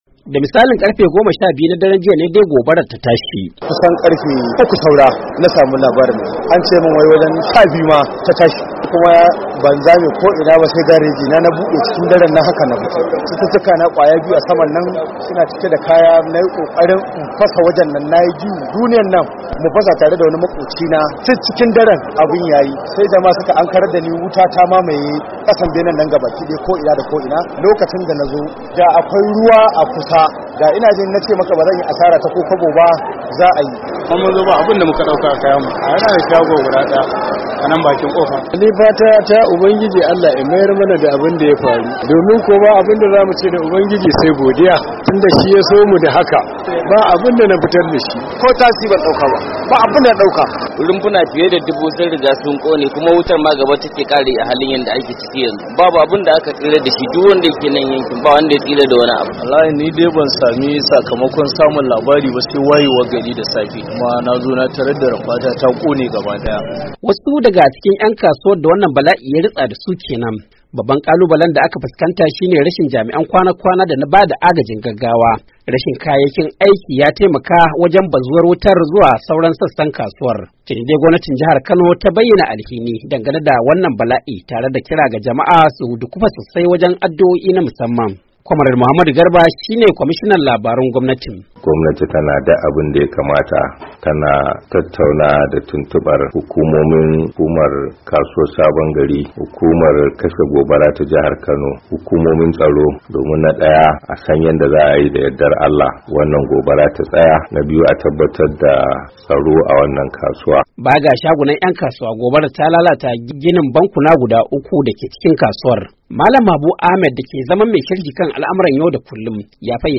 Wasu daga cikin ‘yan kasuwan da wannan balain ya rutsa dasu Kenan.